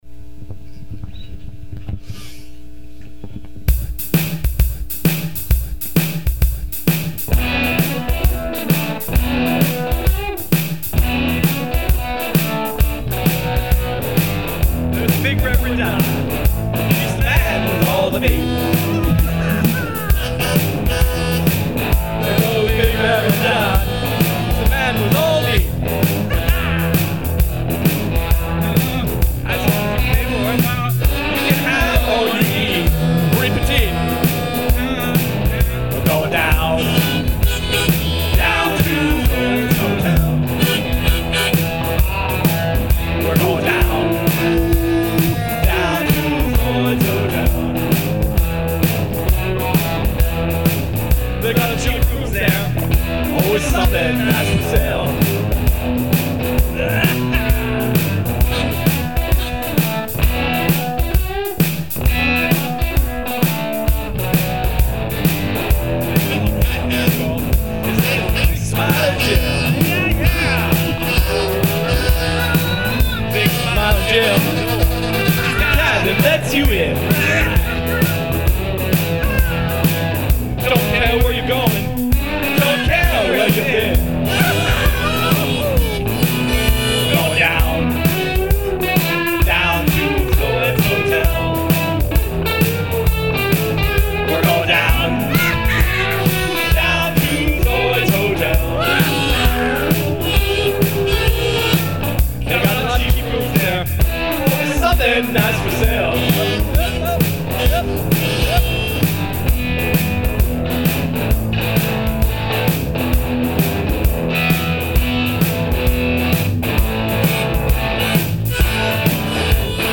FUNKY ROCK N ROLL FRIDAY